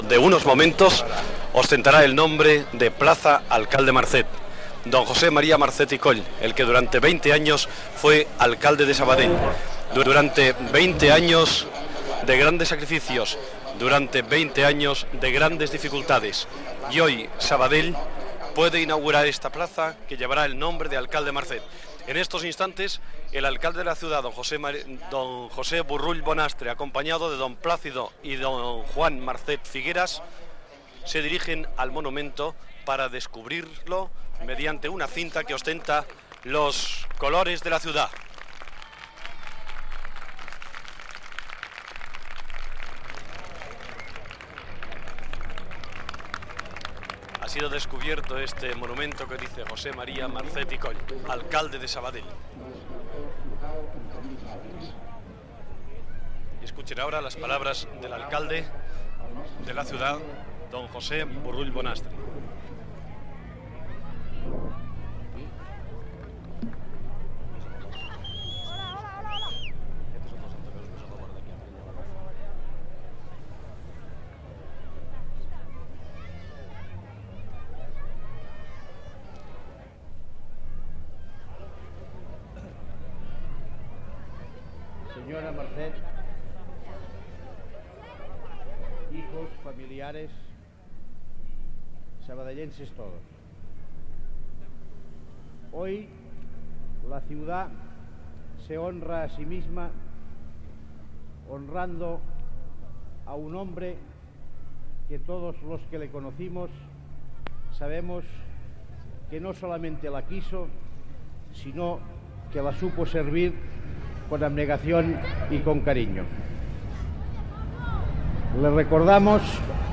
Inauguració de la plaça Alcalde Marcet, amb les paraules de l'alcalde Josep Borrull Gènere radiofònic Informatiu